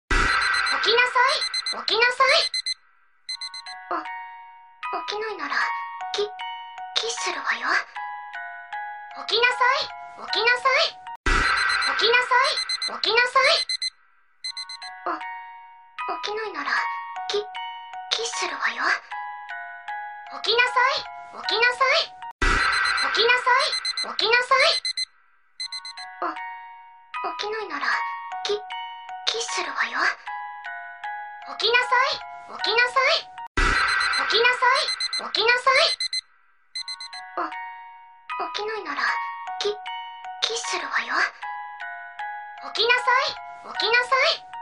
Nhạc Chuông Báo Thức Hoạt Hình